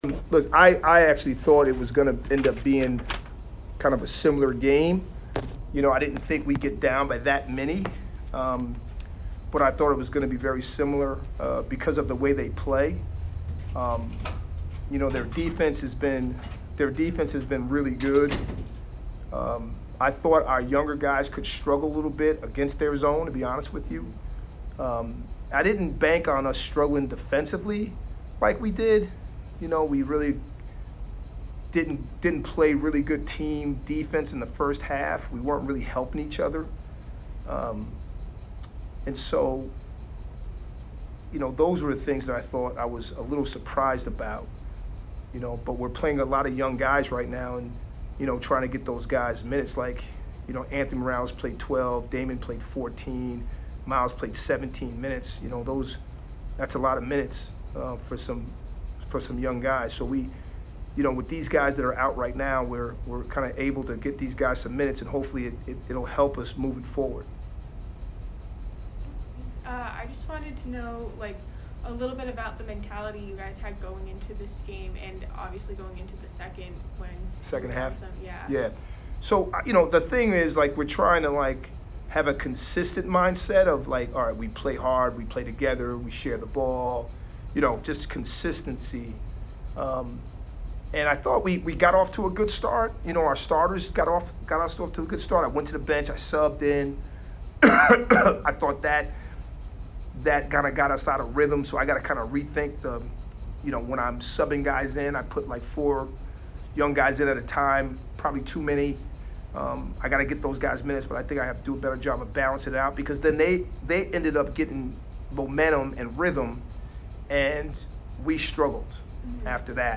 Merrimack MBB Press Conference